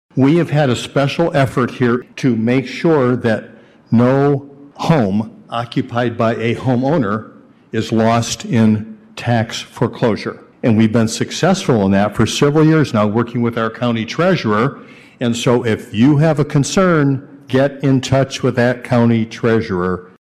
Mayor Dave Anderson says Kalamazoo County residents can get extra help when they have trouble paying, to avoid foreclosures.